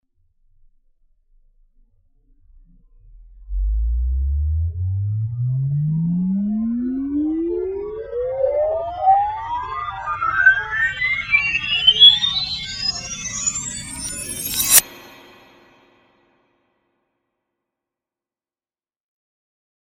Very scary and spooooky alien sounds